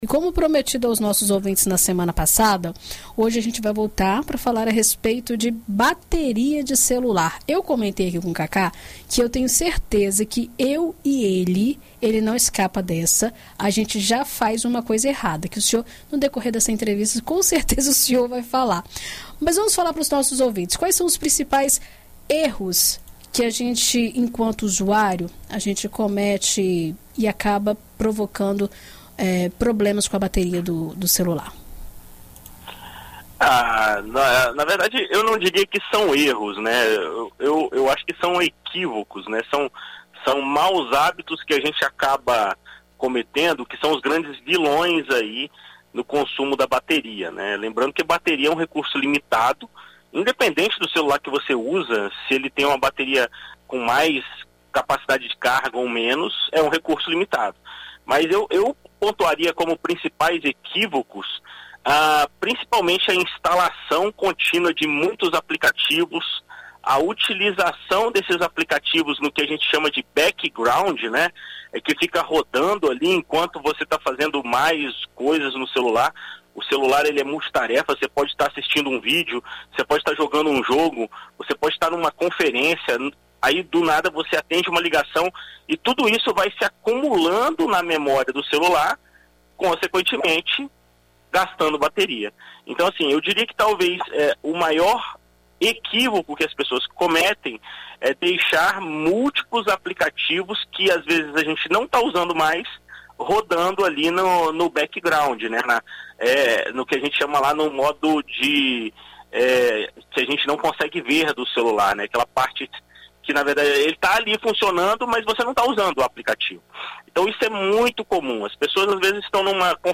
ENT-BATERIA-CELULAR.mp3